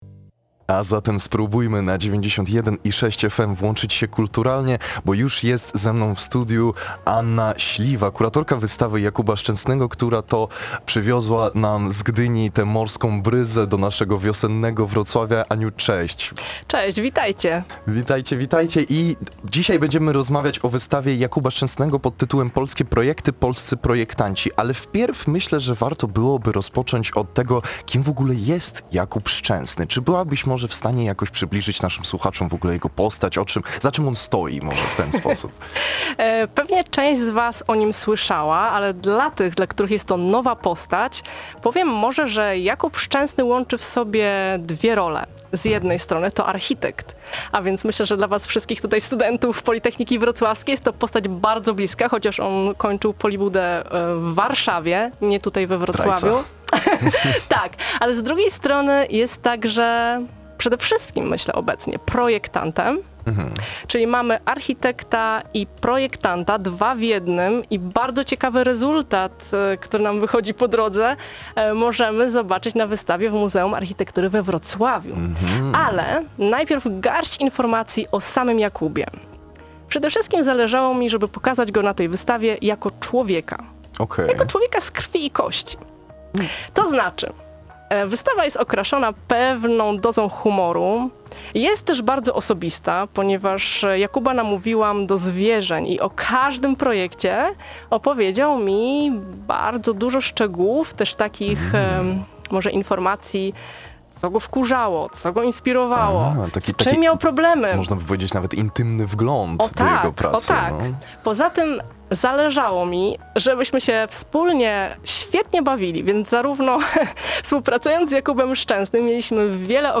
Więcej o jego postaci mówi nasza gościni.
Rozmowa-PK-02.04.mp3